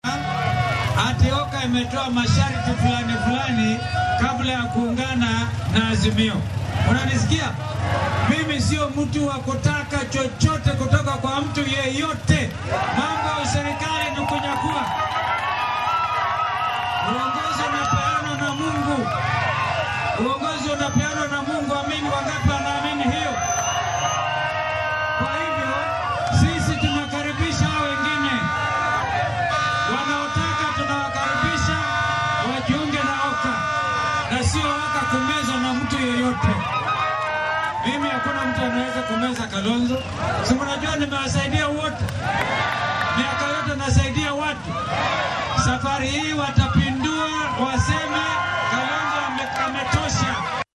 Madaxa xisbiga Wiper kana mid ah hoggamiyaasha isbeheysiga OKA Kalonzo Musyoka ayaa meesha ka saaray sheegashada ah in isbeheysigan uu shuruud ku xiray ka mid noqoshada higsiga mideynta ee Azimio La Umoja islamarkaana uu hoggaamiyo Raila Odinga. Madaxweyne ku xigeenkii hore ee dalka oo hadalkan ka jeediyay deegaanka Huruma ee magaalada Nairobi ayaa sheegay in madaxda OKA ay yihiin hoggaamiyeyaal daacad ah oo aysan shuruudo ku xiri karin dadka kale si ay ula shaqeeyaan.